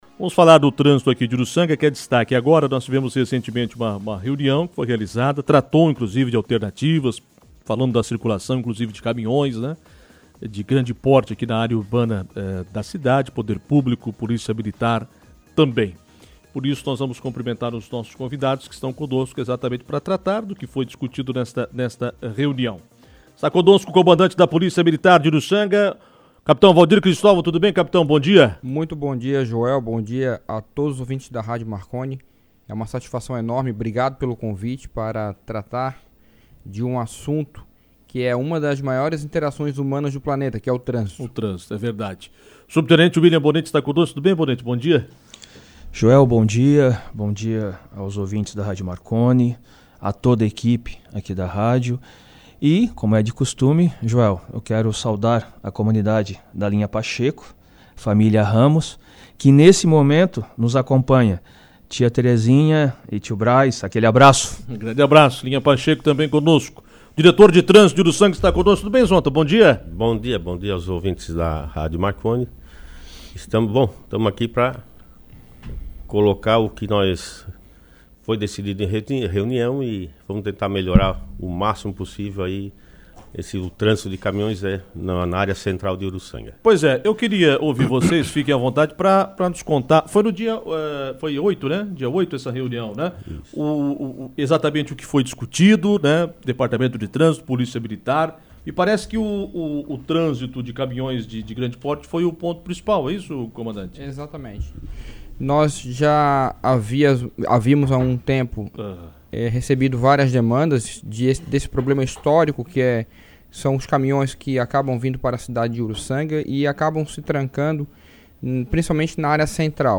Em entrevista, o diretor de Trânsito de Urussanga, Rodrigo Zonta, exemplificou que os caminhões de grande porte acabam acessando o conhecido Trevo das Bandeiras, em direção a rua Angélica Collodel Bettiol.